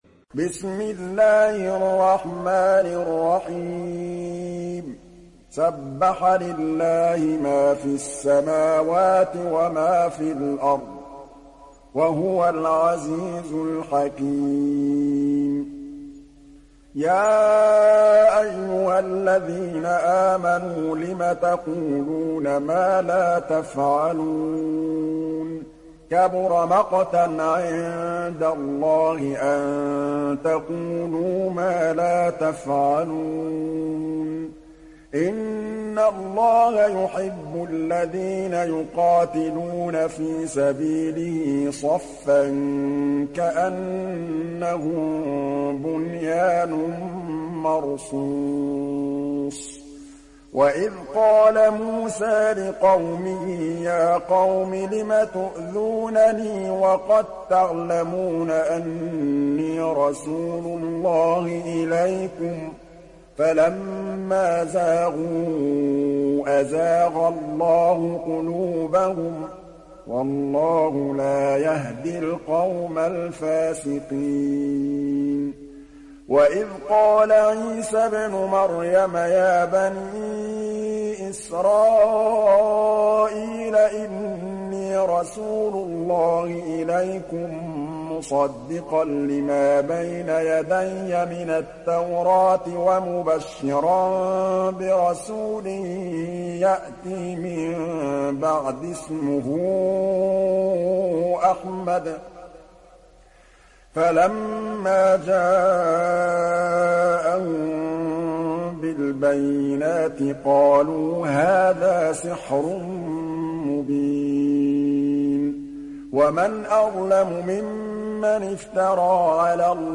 Sourate As Saf Télécharger mp3 Muhammad Mahmood Al Tablawi Riwayat Hafs an Assim, Téléchargez le Coran et écoutez les liens directs complets mp3